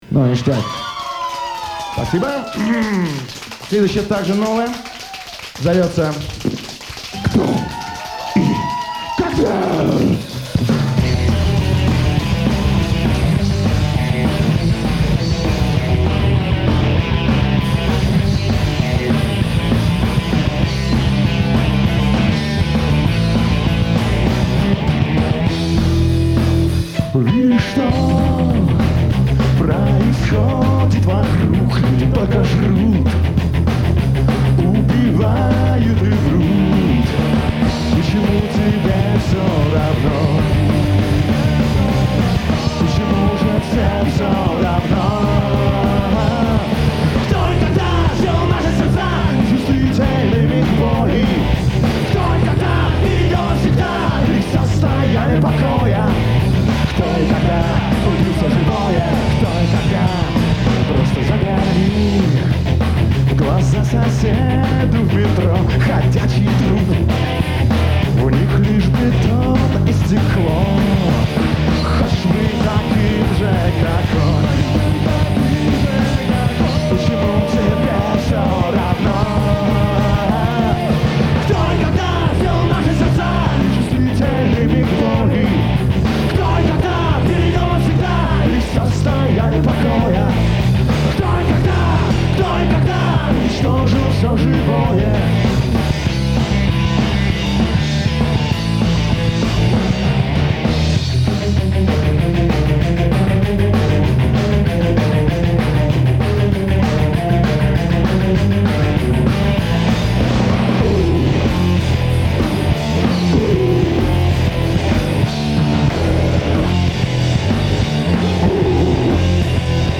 Нашёл и оцифровал кассету